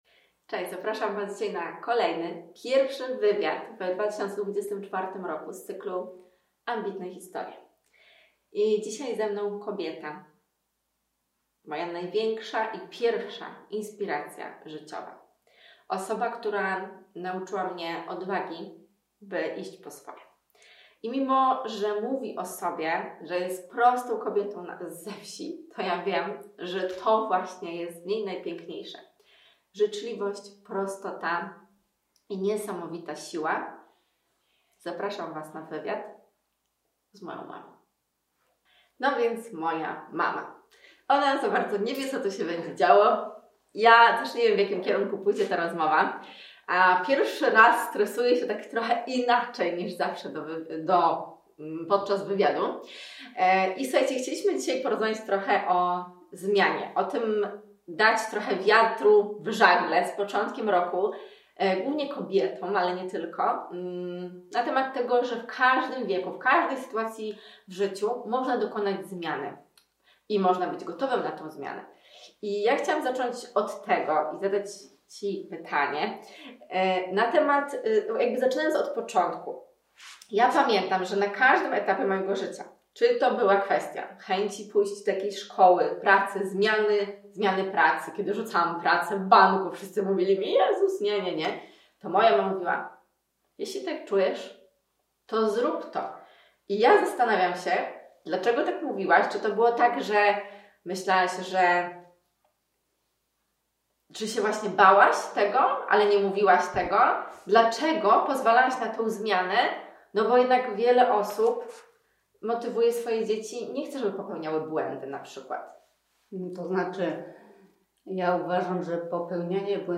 Dzisiaj wywiad z cyklu Ambitna Historia z osobą, która jest dla mnie pierwszą i największą inspiracją życiową! Z kobietą, która nauczyła mnie odwagi, by iść po swoje.